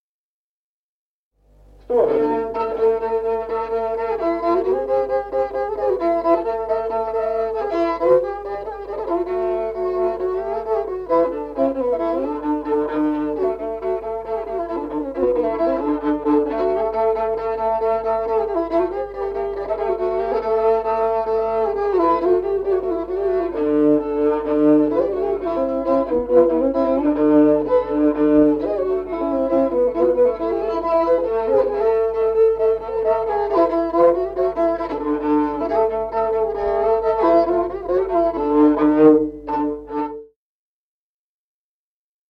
Музыкальный фольклор села Мишковка «Сербиянка», партия 2-й скрипки.